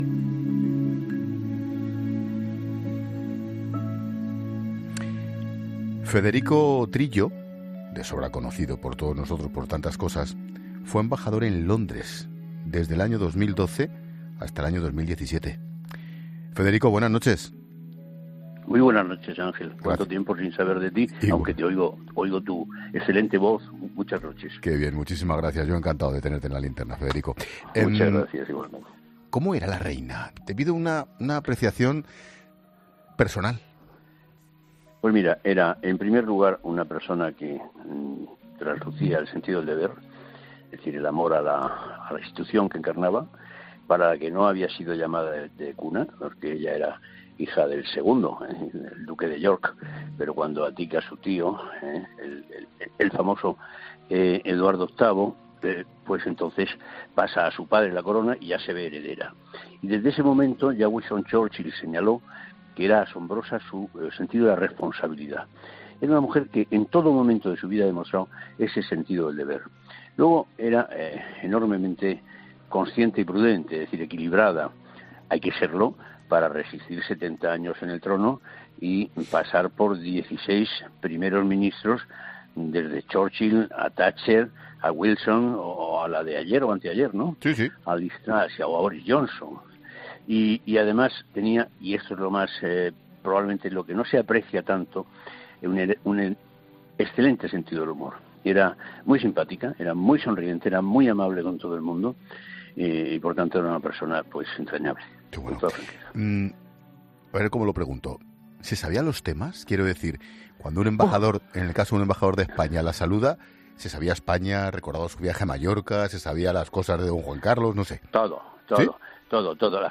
Federico Trillo hace balance en 'La Linterna' sobre la figura de Isabel II